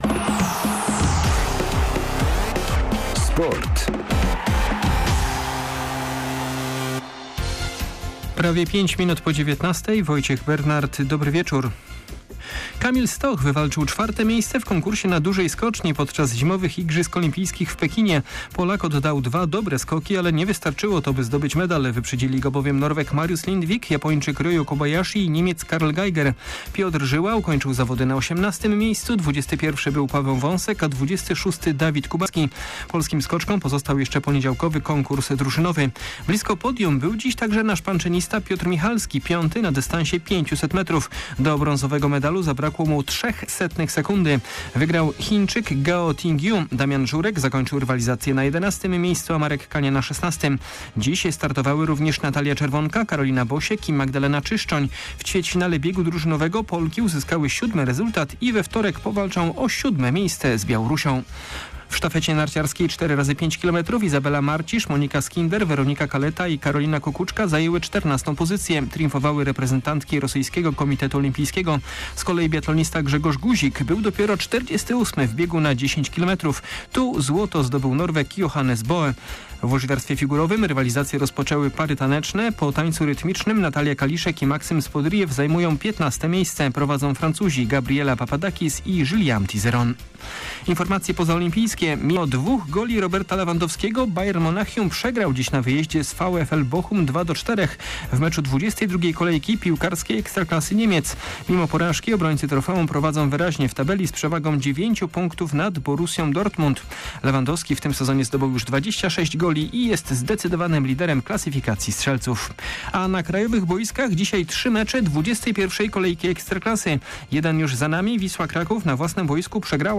12.02.2022 SERWIS SPORTOWY GODZ. 19:05